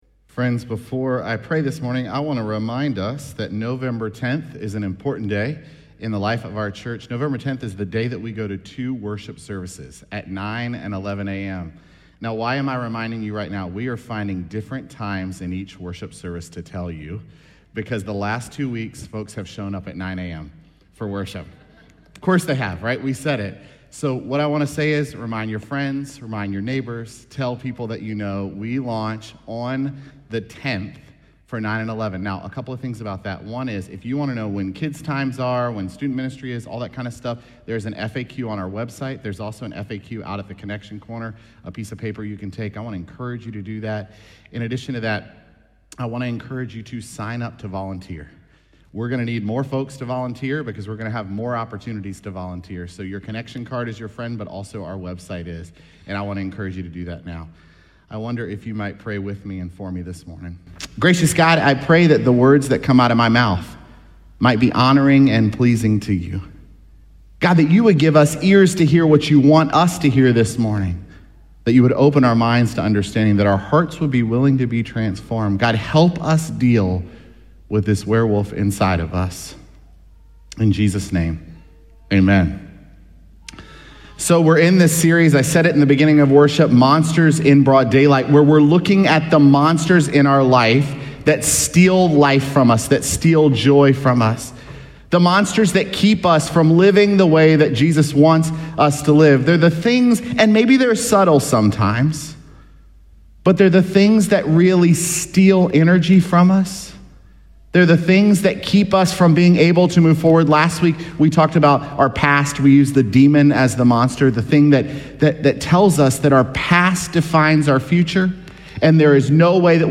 Sermons
Sept15SermonPodcast.mp3